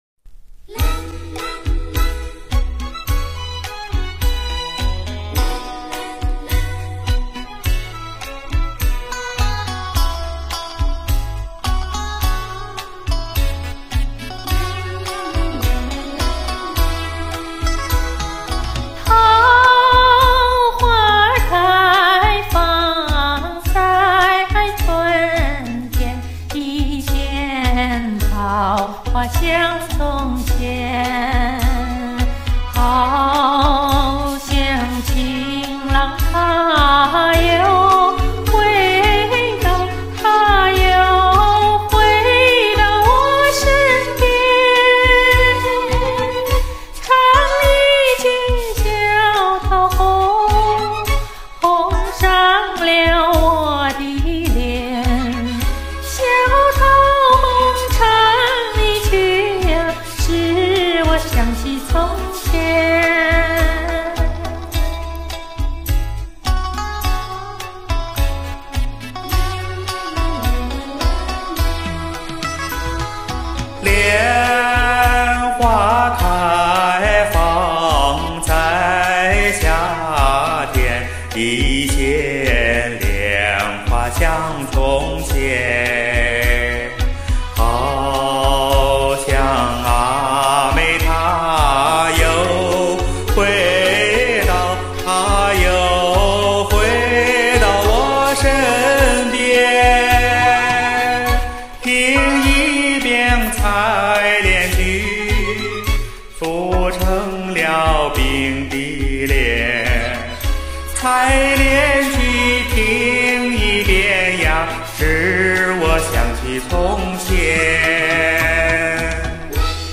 传统的春夏秋冬四季歌的小调样式，歌词也是非常美好。